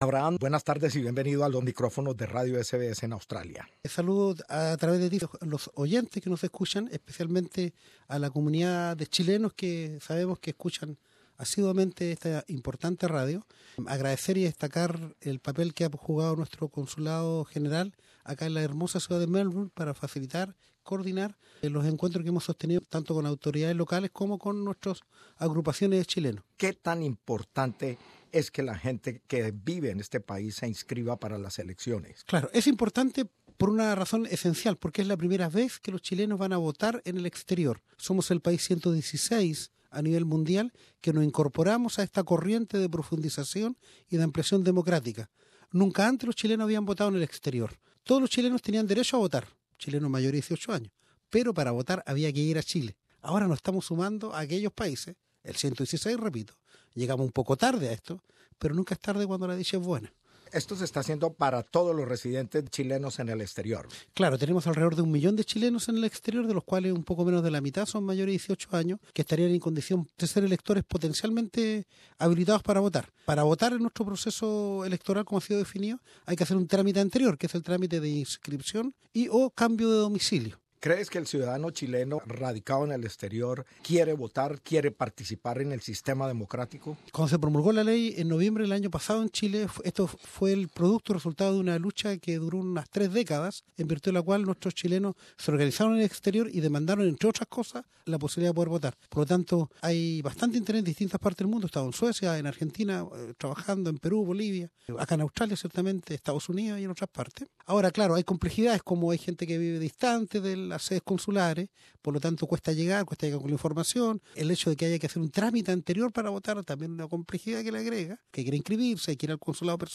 De eso nos habla el Director de la organización de comunidades en el exterior (Dicoex) Abraham Quezada Vergara que ha estado de visita en Australia.